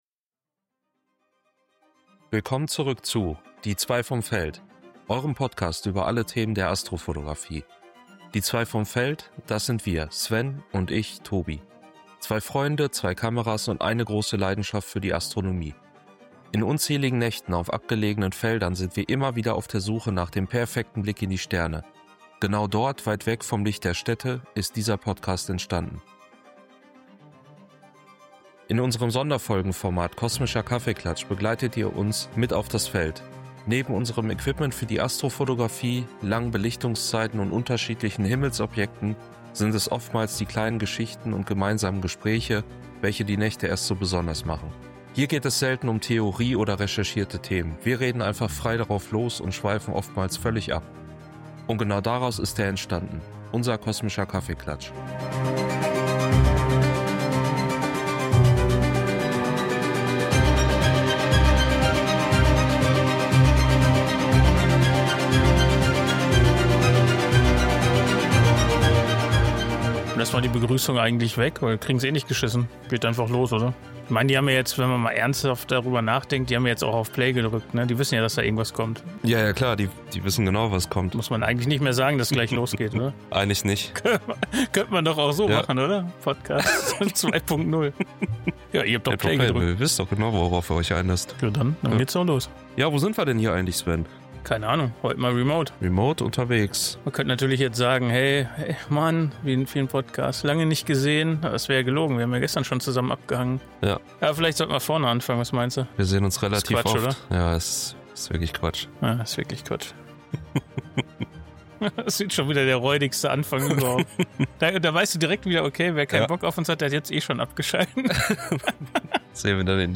Hier geht es selten um Theorie oder recherchierte Themen, wir reden einfach frei drauf los und schweifen oftmals völlig ab.